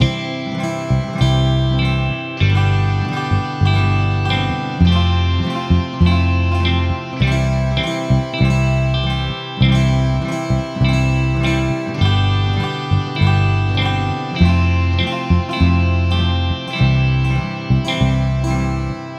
Lesson 4: Creating using the Guitar
lesson-4-example-guitar.wav